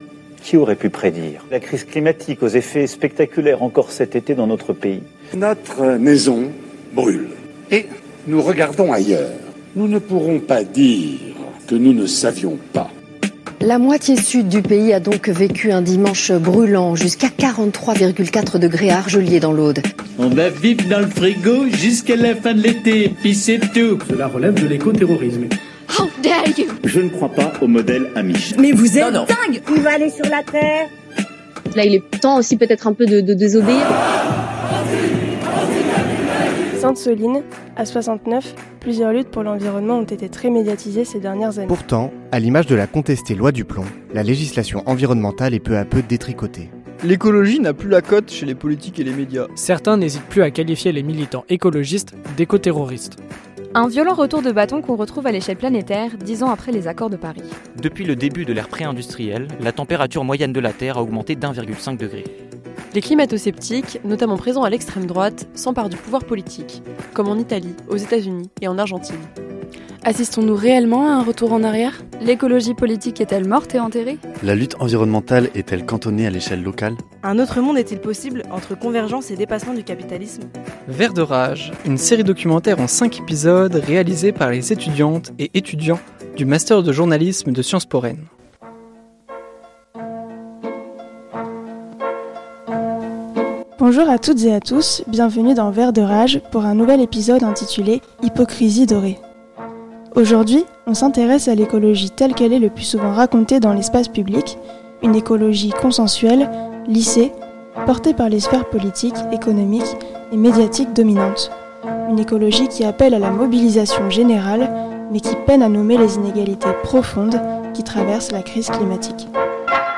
"Hypocrisie dorée" est le troisième épisode de la série documentaire Vert de rage.